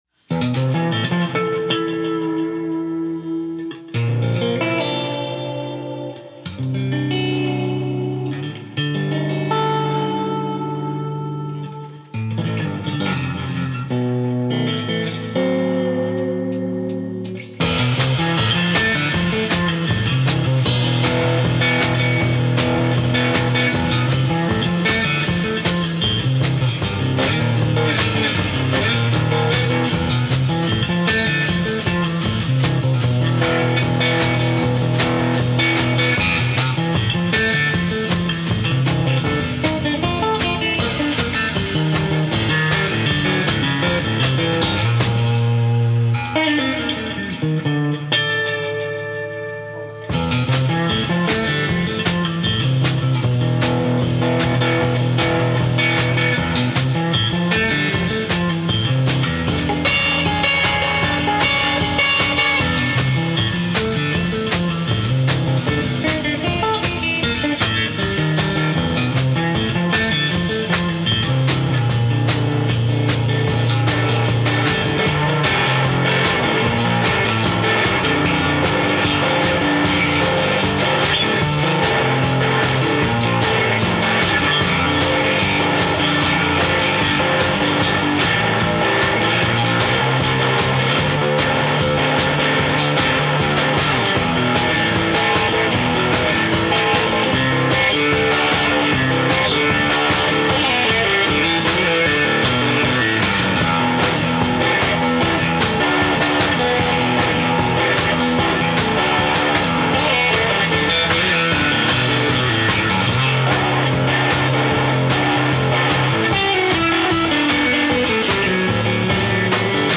Guitar
and drums